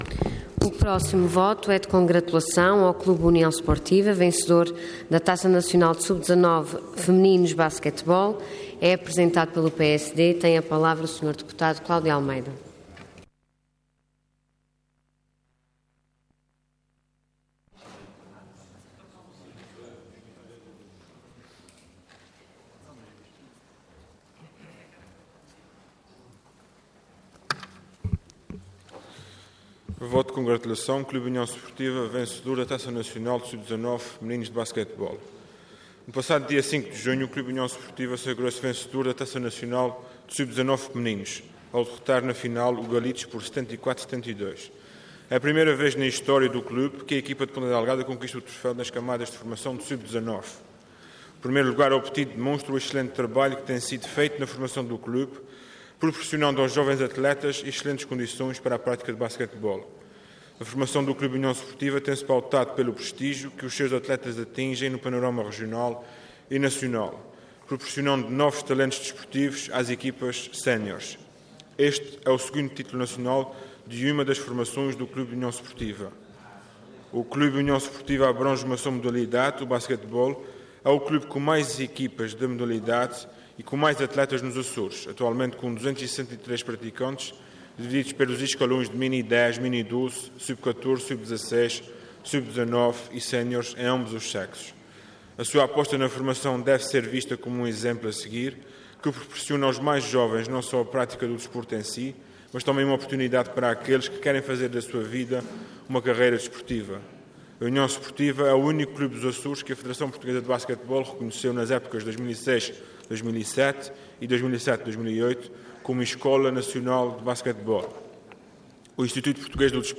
Detalhe de vídeo 13 de julho de 2016 Download áudio Download vídeo Processo X Legislatura Clube União Sportiva: Vencedor da Taça Nacional de Sub 19 femininos de basquetebol Intervenção Voto de Congratulação Orador Cláudio Almeida Cargo Deputado Entidade PSD